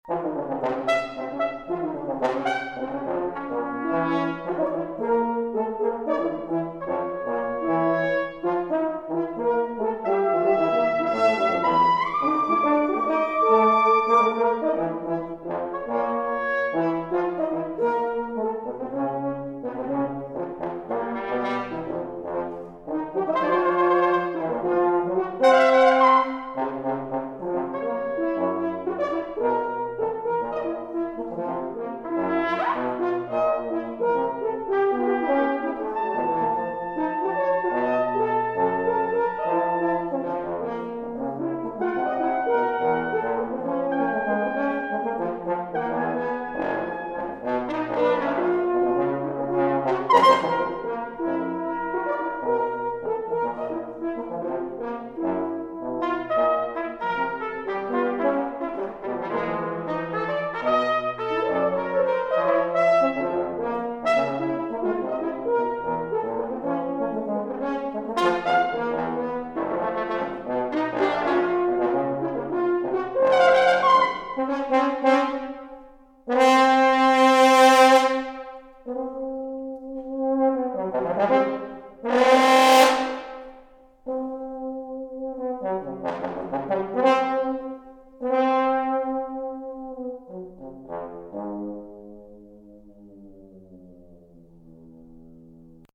trumpet
trombone